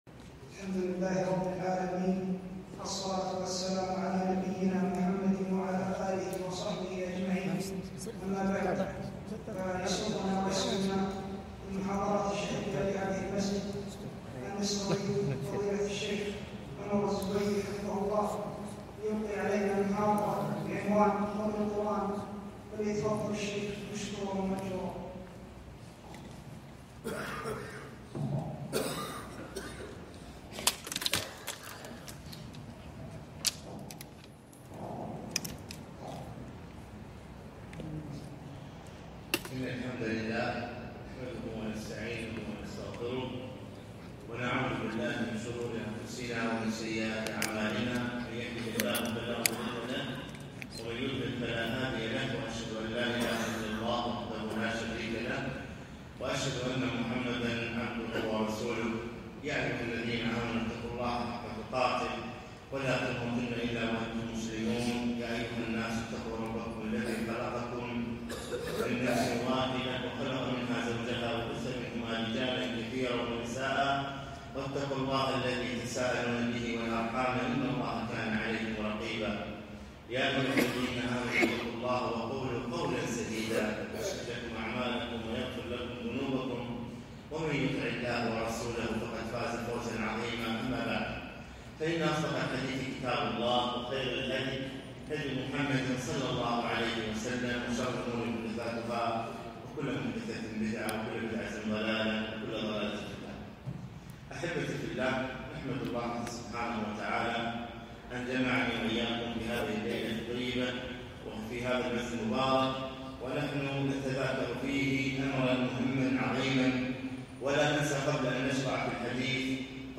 محاضرة - فضل القرآن